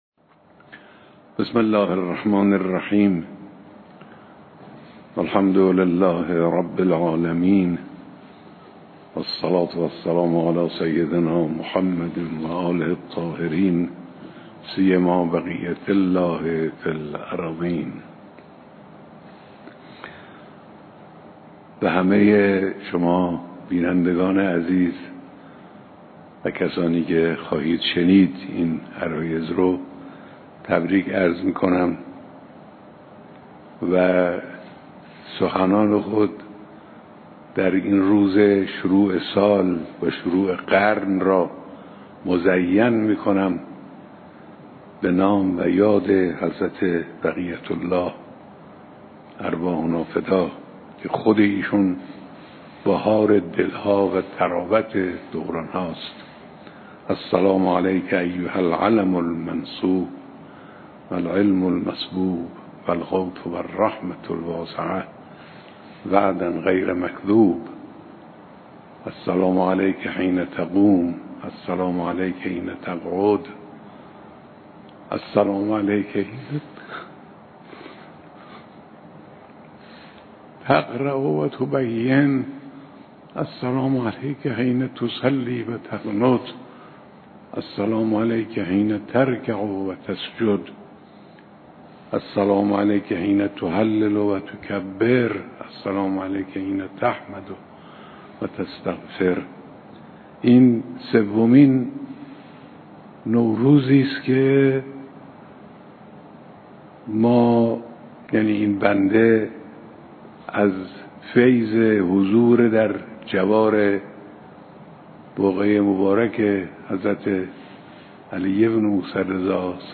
صوت کامل بیانات رهبر معظم انقلاب در سخنرانی نوروزی خطاب به ملت ایران